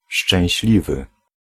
Ääntäminen
IPA: /ø.ʁø/